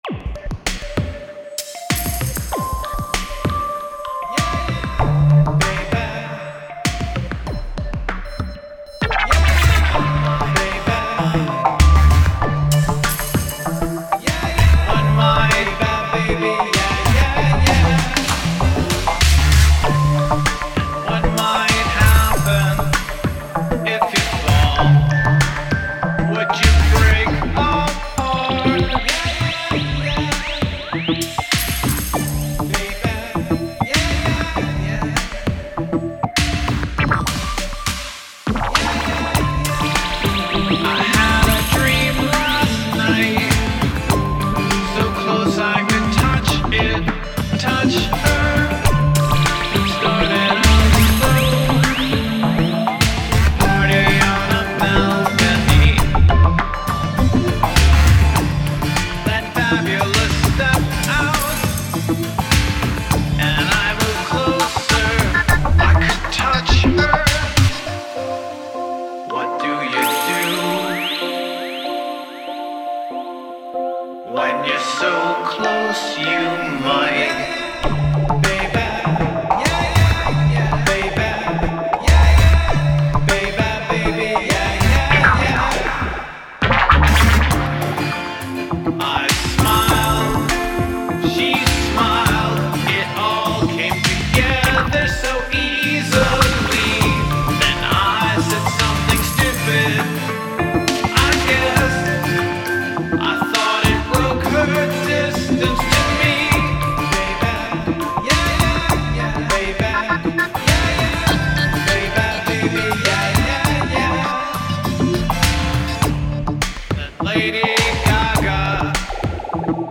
A lot was doing to reduce overlap of sound textures, but it is still way too busy sonically.
There is a muddy melody at the end that a vocal could mirror.
It includes vocals.